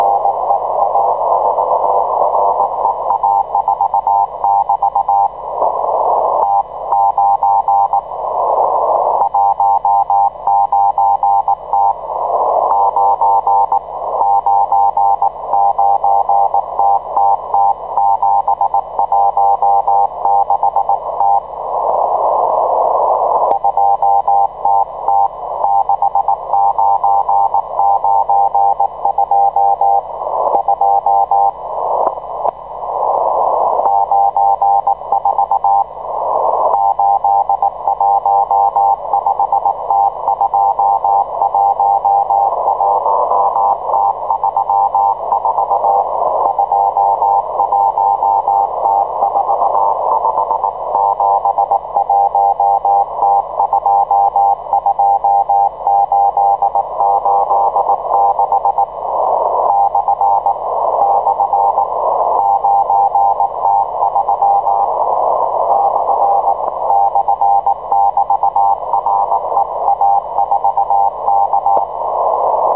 Émissions télégraphiques exotiques
Chalutiers présumés japonnais sur 4191 kHz et commandement de l'aviation à longue portée des forces aériennes russes sur 4179 kHz :